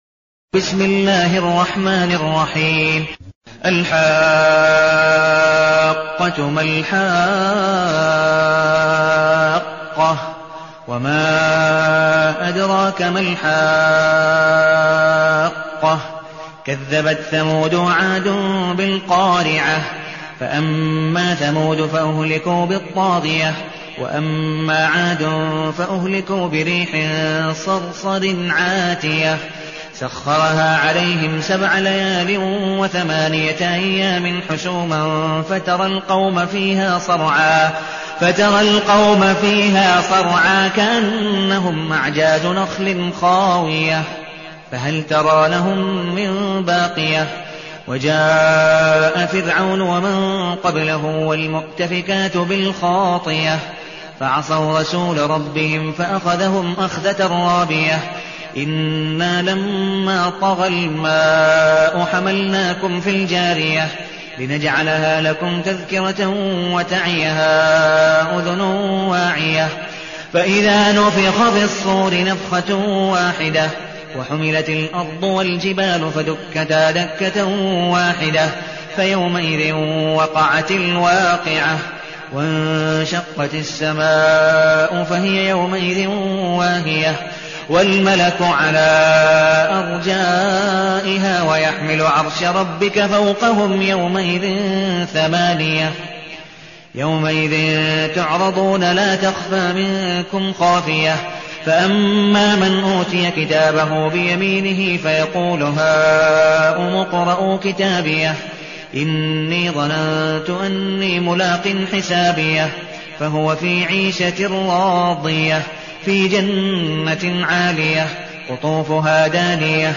المكان: المسجد النبوي الشيخ: عبدالودود بن مقبول حنيف عبدالودود بن مقبول حنيف الحاقة